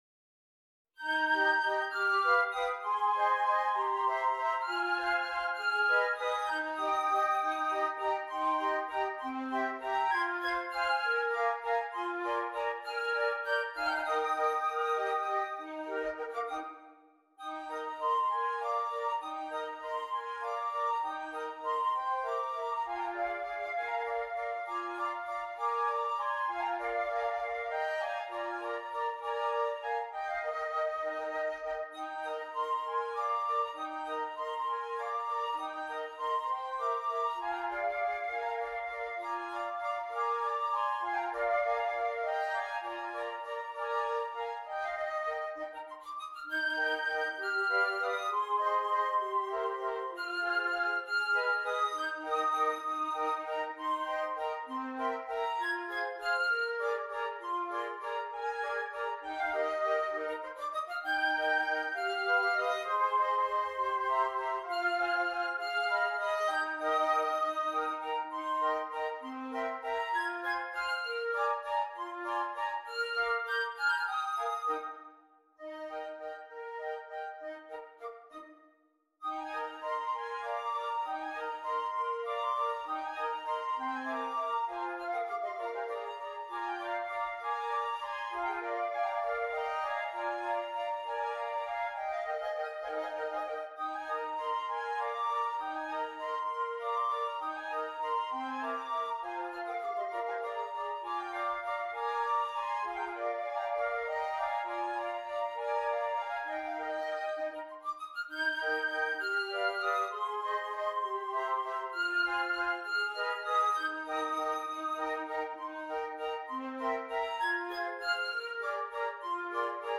5 Flutes
Traditional Mexican Folk Song
In this arrangement, the melody is found in several parts.